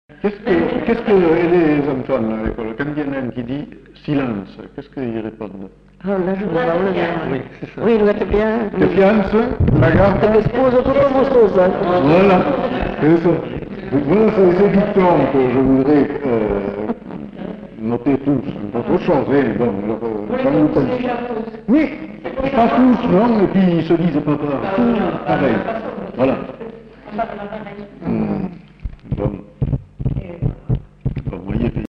Aire culturelle : Bazadais
Lieu : Cazalis
Genre : forme brève
Effectif : 1
Type de voix : voix de femme
Production du son : récité
Classification : formulette enfantine